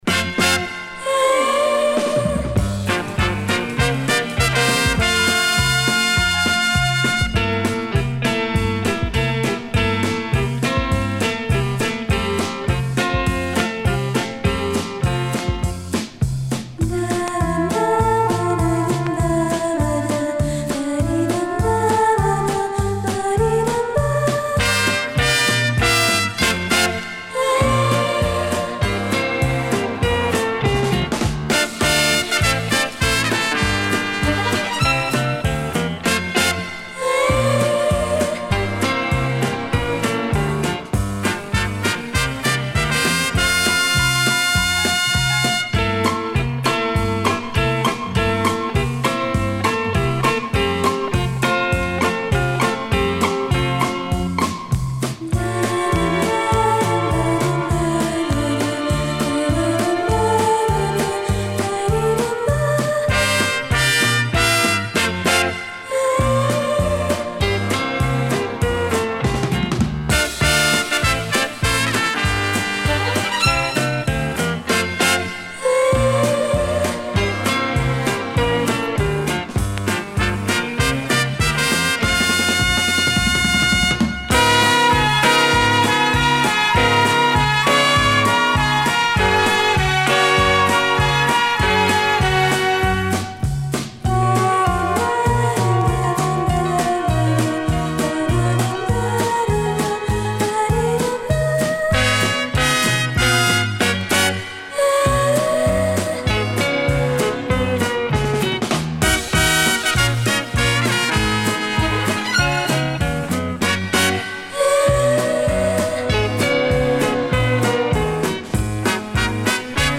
Всюду звучат, затаившие дыхание, женские вокалы.
Genre: Instrumental Pop, Back Vocal,
Easy Listening